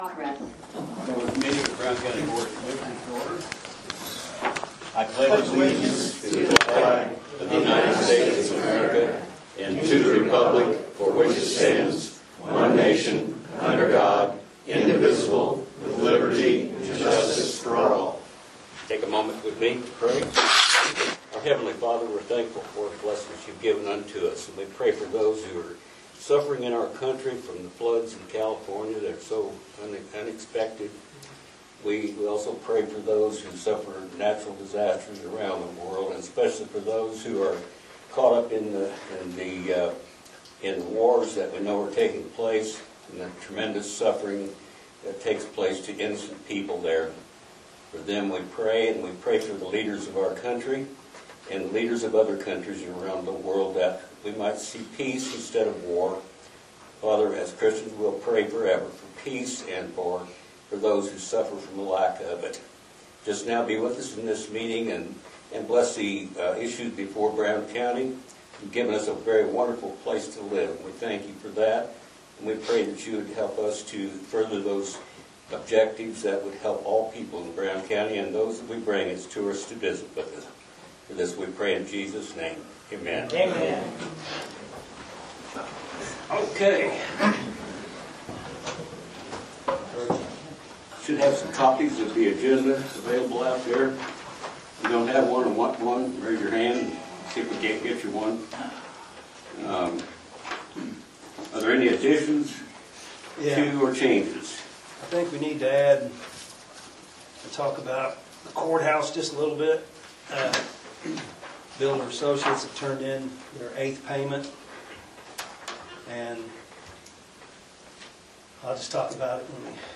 Agenda Commissioner Meeting Feb 21, 2024